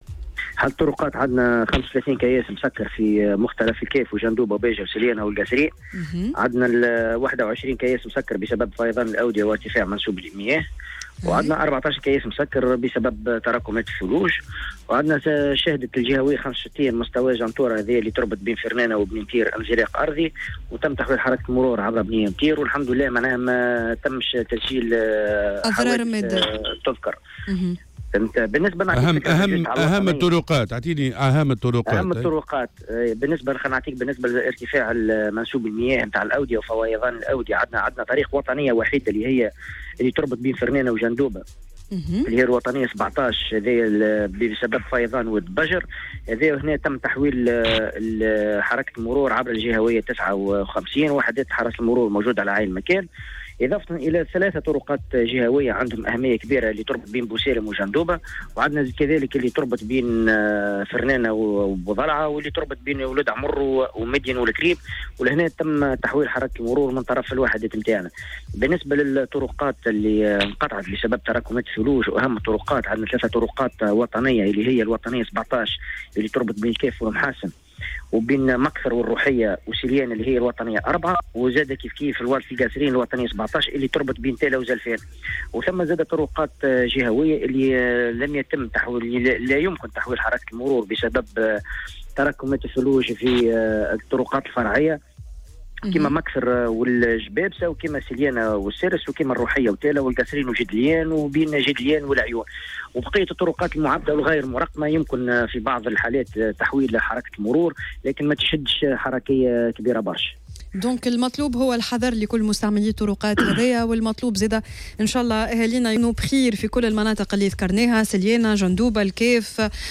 في مداخلة له منذ قليل في صباح الورد